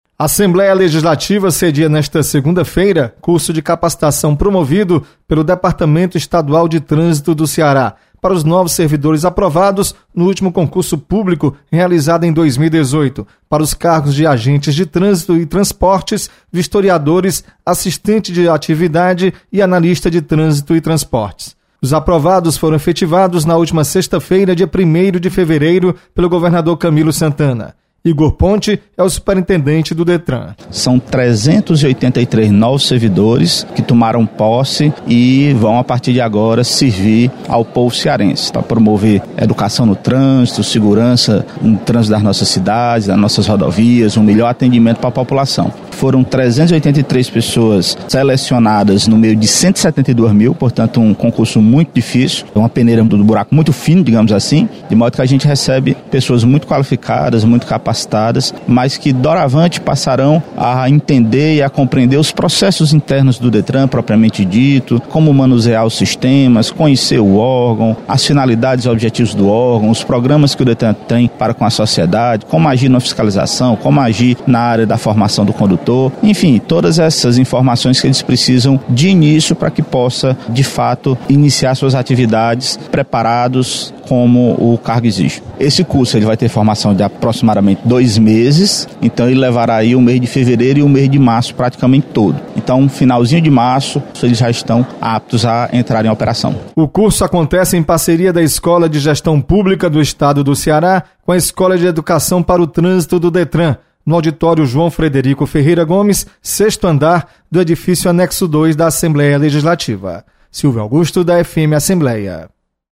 Assembleia sedia curso para capacitação de agentes de trânsito. Repórter